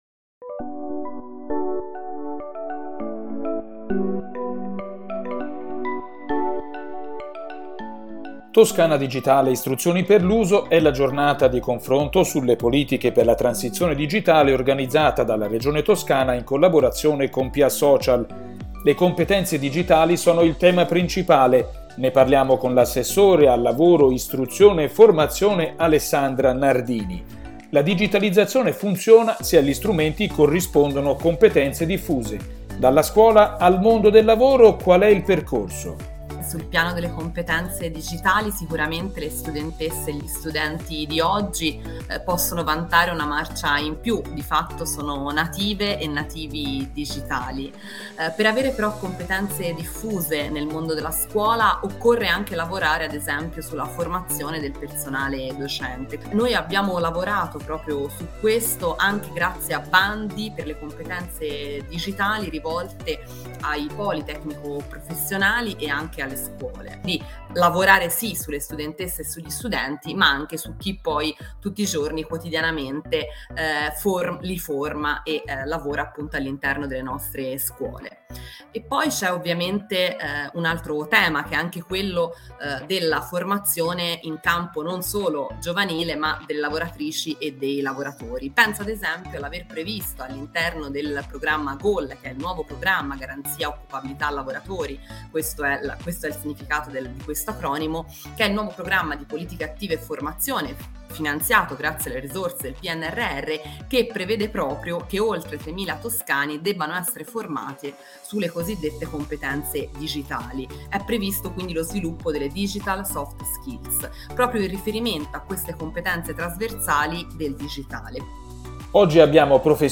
Lunedì 11 luglio al Teatro della Compagnia di Firenze
Ascolta l'intervento dell'Assessora al Lavoro, istruzione e formazione, Alessandra Nardini: